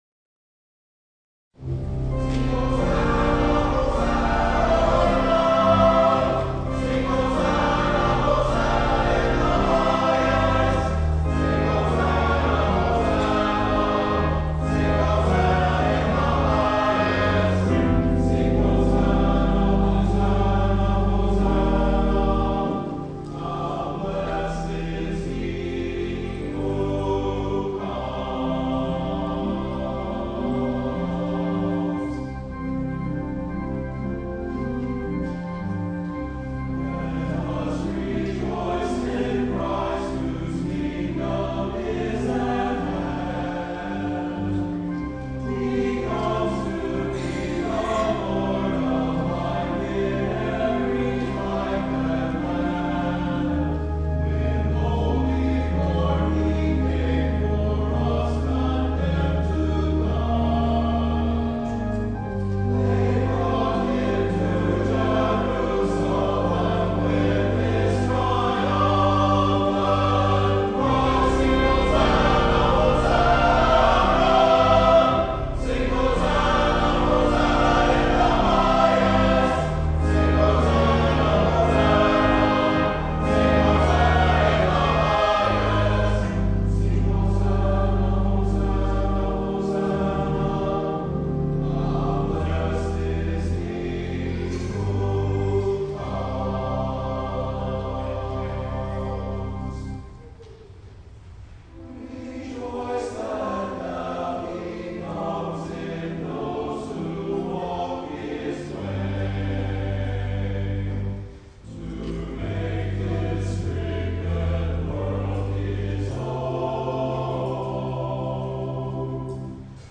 Audio from Selected Sunday Services - (mp3 format)
Sunday of the Passion: Palm Sunday - April 1, 2012
Offertory Anthem: How Blest is He Who Comes -- J. Purifoy SJC Choir
organ